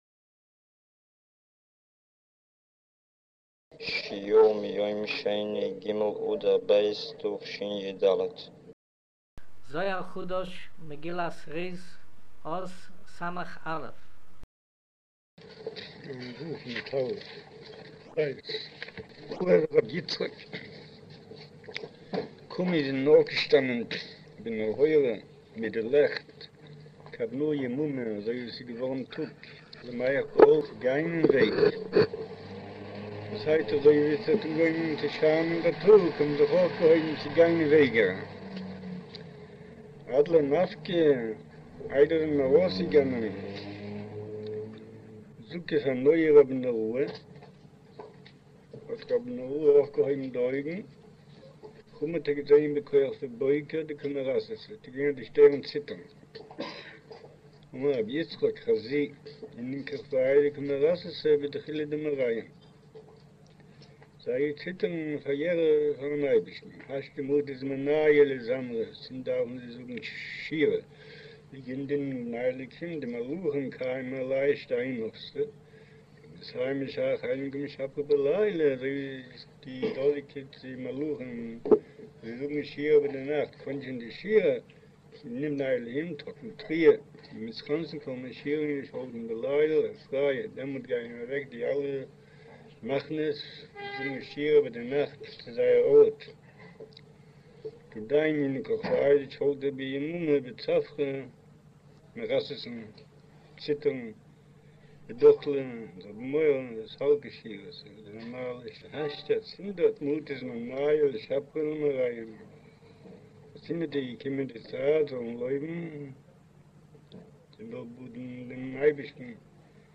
אודיו - שיעור